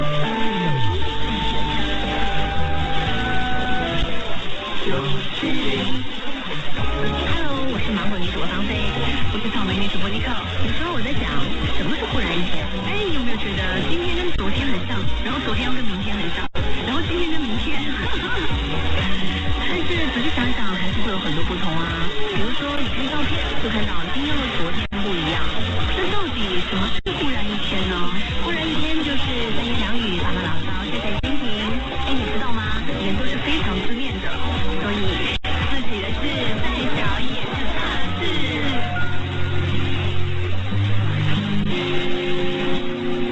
Tilalle tuli tämä 97.0 Hostess Radio eli kiinaksi 9-7-0 (jiu-qi-ling) Nǚ zhǔbō, joka nimensä mukaisesti käyttää vain naisjuontajia.